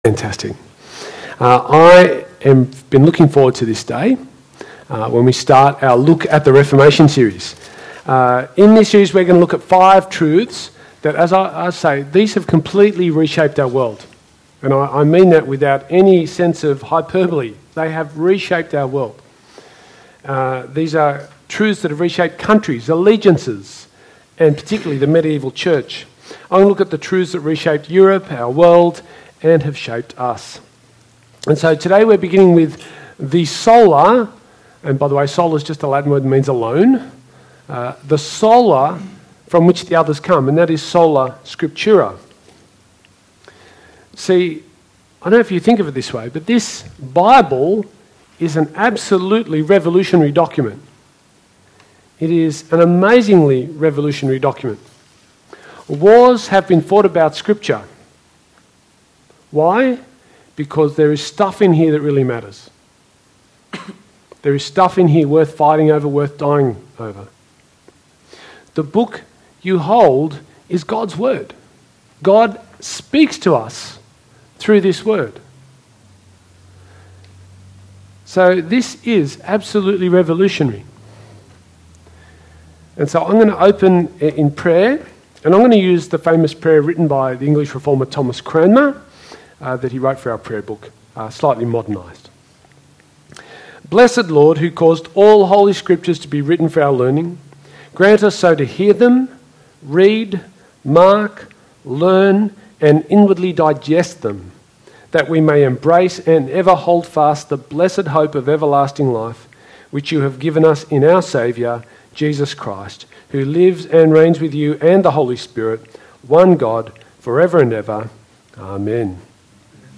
SERMON – According to Scripture Alone